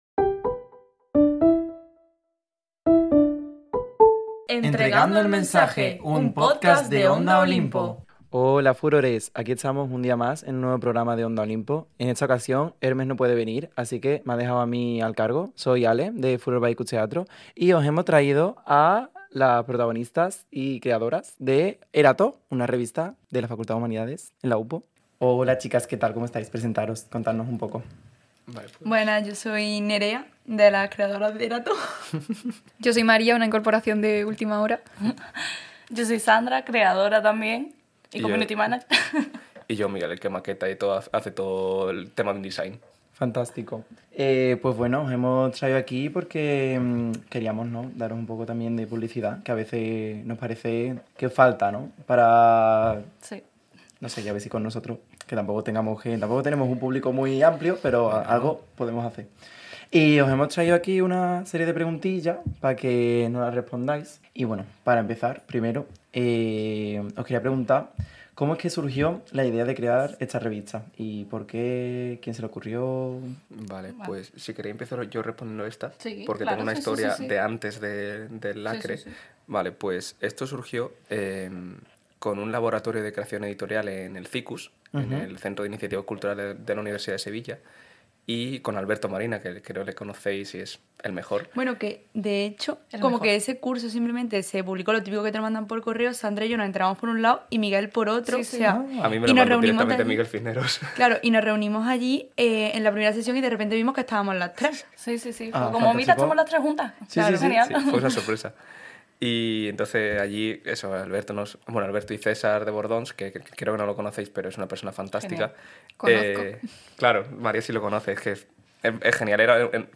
Onda Olimpo - Entregando el mensaje "Entrevista Erató"